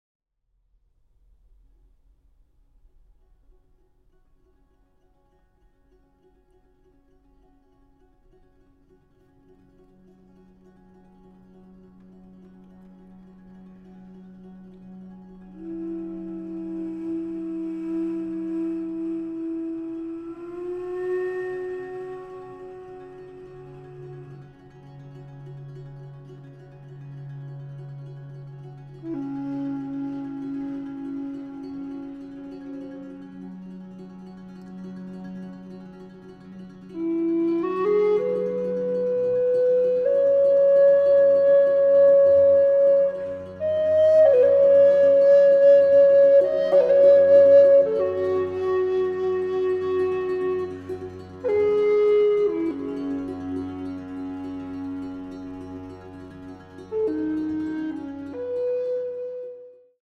Recorder